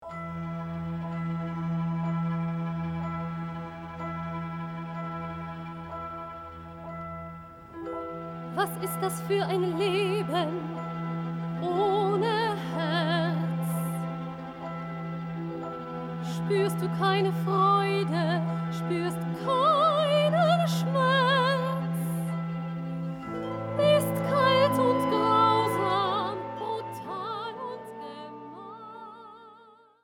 Piano & Vocal Score
Piano + Vocals